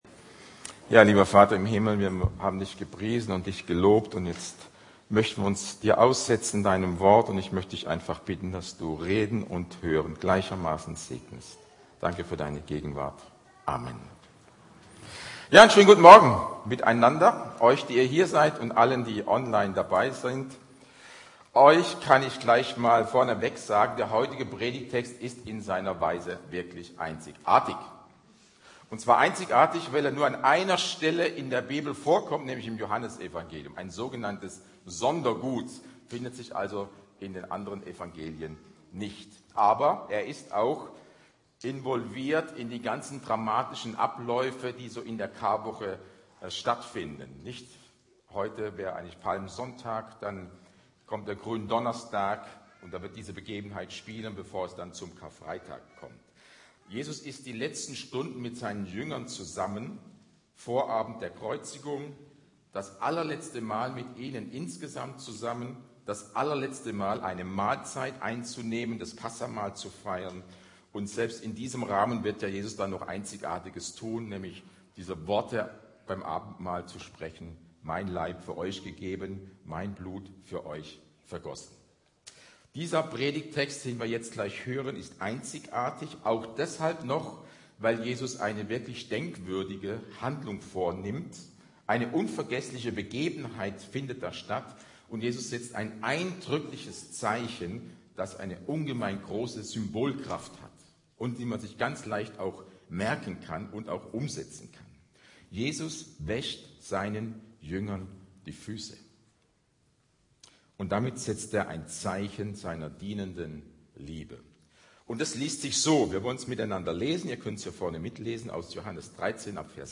Predigt Evangelien